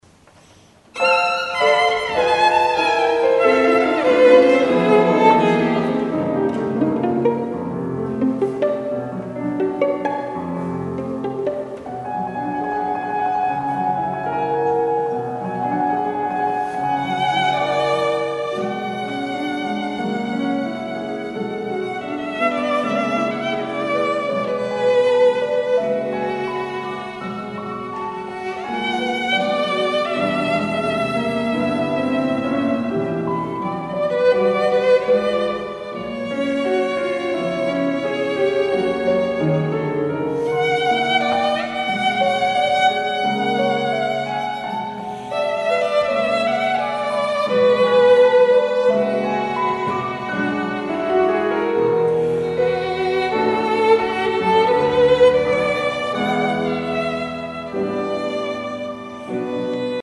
This is the live record. Sorry for some ambiance noises.